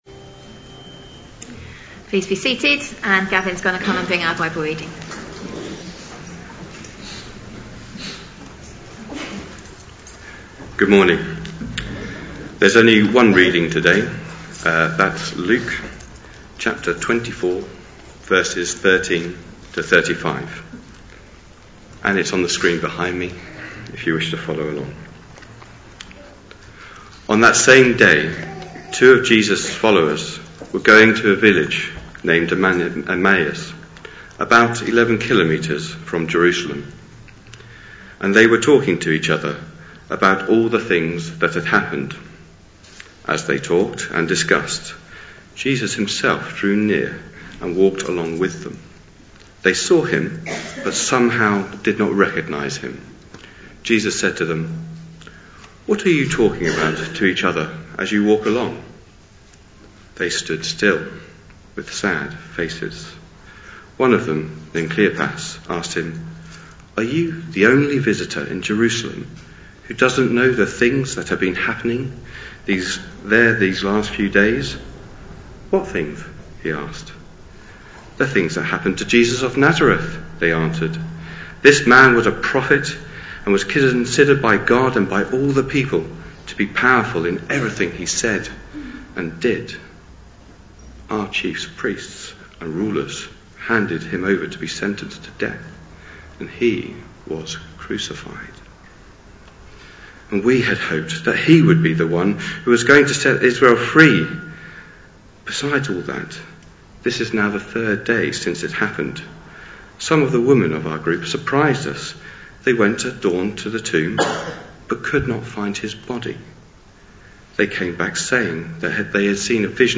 A sermon preached on 29th April, 2018.